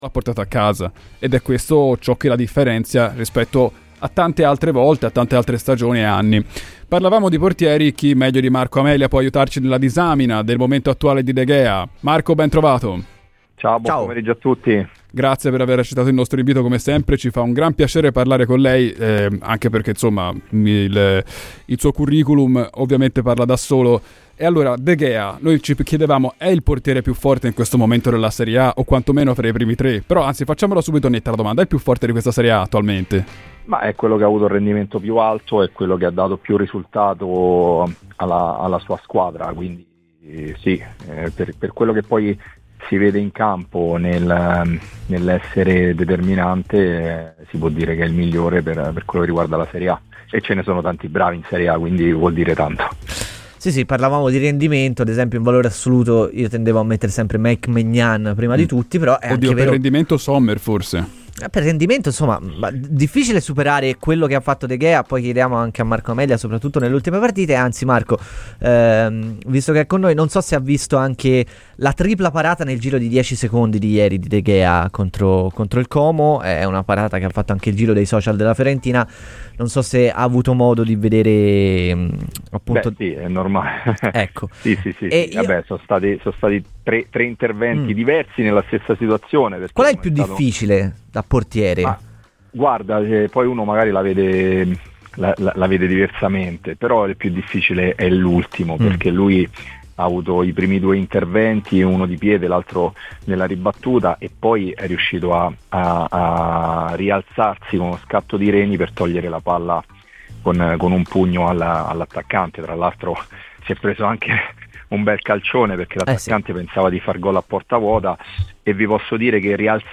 ASCOLTA TUTTA L'INTERVISTA DAL PODCAST SU PALLADINO E GLI ALTRI PORTIERI VIOLA